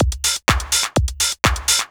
Klarks Beat_125.wav